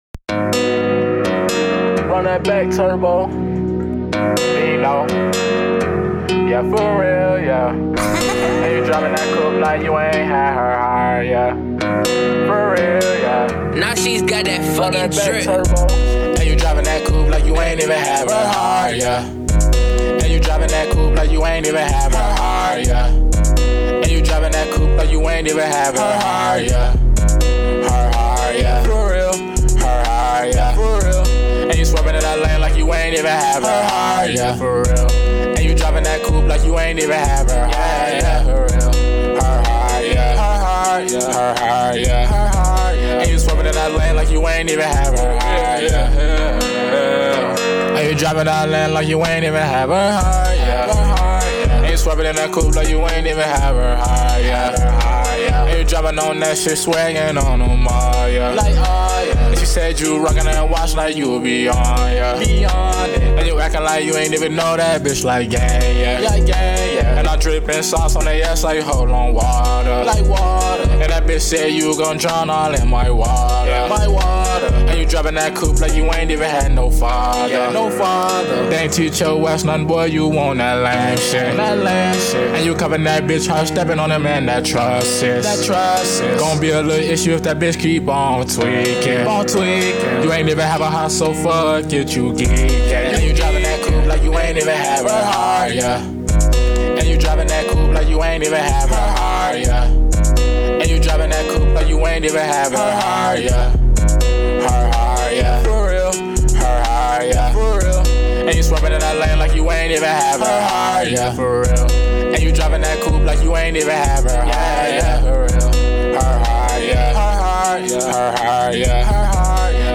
Melodic Guitar Dreamy